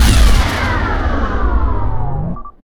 Index of /V1 Installs/dystopia1.3_server/dystopia/sound/weapons/plasma
fire3.wav